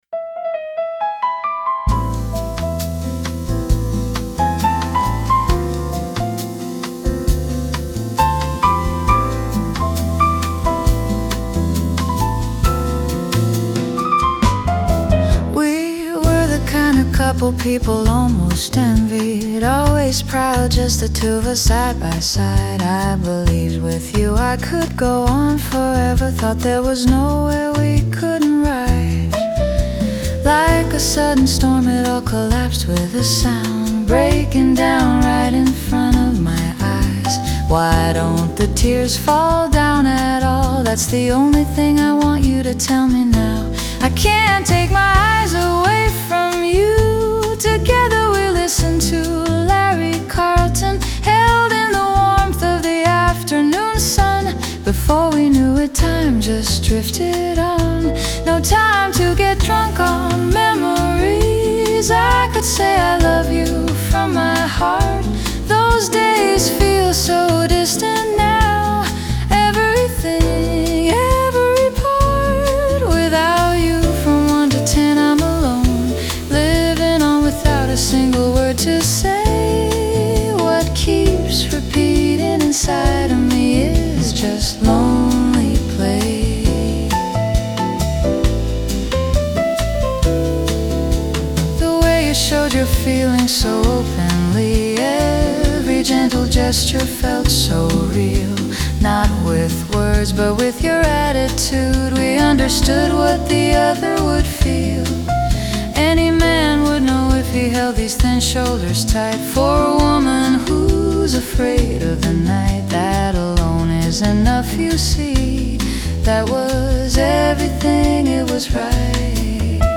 ・・☆緩やかな、穏やかな曲は「癒し」です☆Chopin Nocturne E Flat Major Op.9 No.2を聴く・・・癒され「免疫力向上」すれば、騒乱の世の中になっても力強く生きていく勇気が湧いてくる